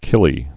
(kĭlē)